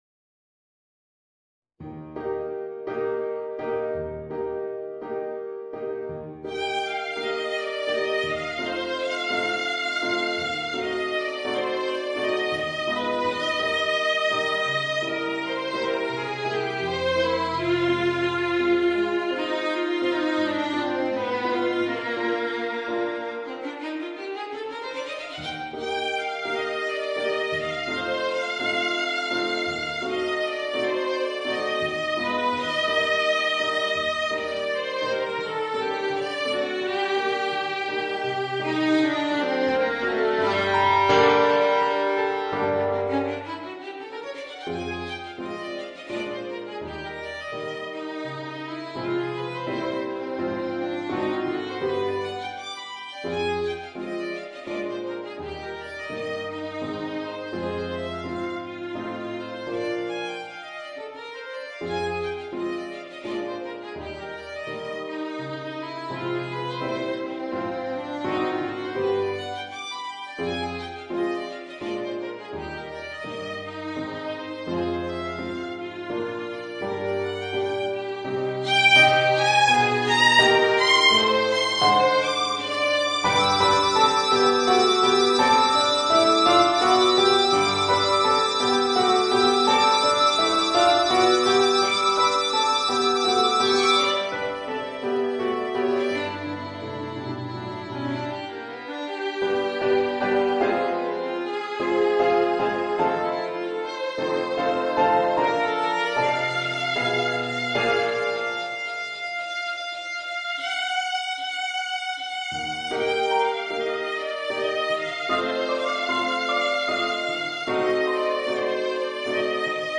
Voicing: Violin and Piano